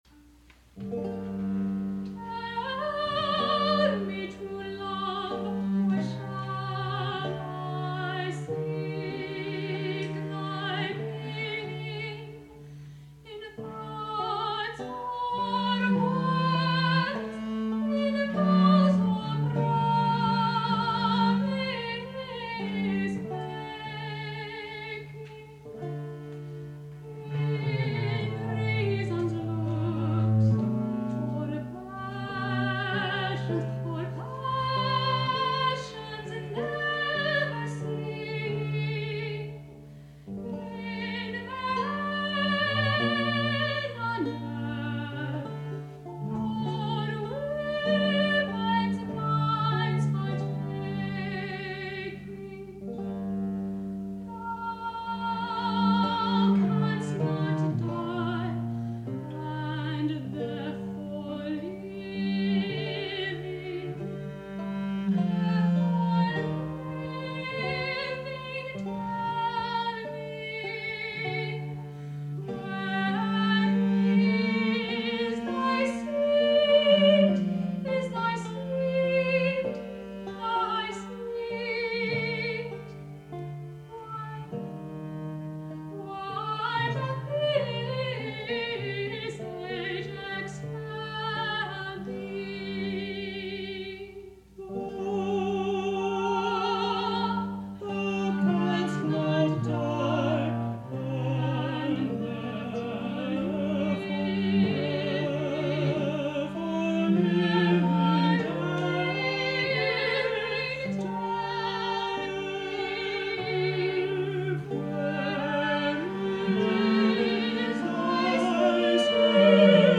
This dialogue appeared in ‘A Pilgrim’s Solace’ published in 1612, dedicated to Lord Thomas Howard de Walden.
soprano
countertenor